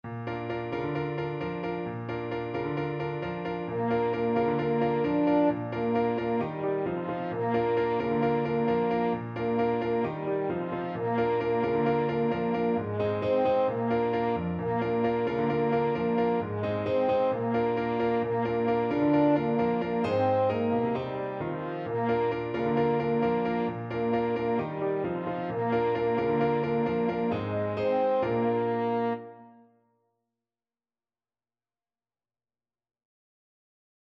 French Horn version
4/4 (View more 4/4 Music)
Fast =c.132
French Horn  (View more Easy French Horn Music)
Traditional (View more Traditional French Horn Music)
Caribbean Music for French Horn